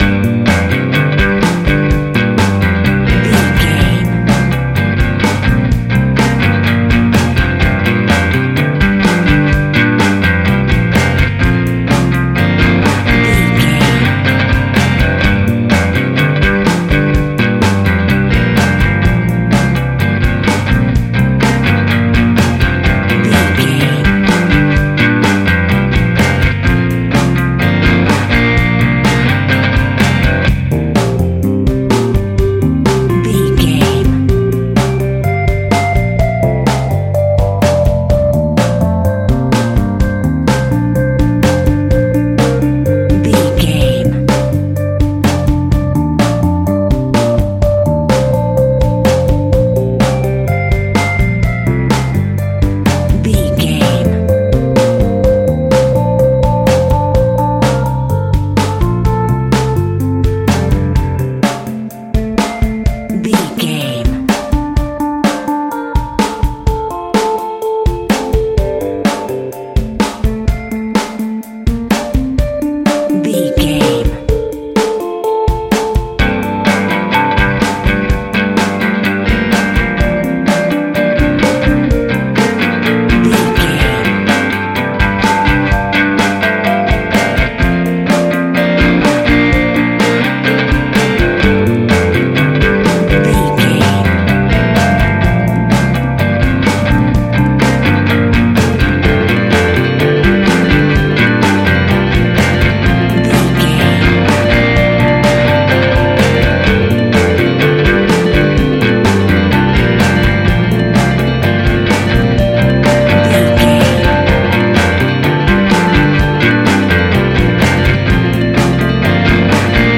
Aeolian/Minor
uplifting
bass guitar
electric guitar
drums
cheerful/happy